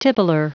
Prononciation du mot tippler en anglais (fichier audio)
Prononciation du mot : tippler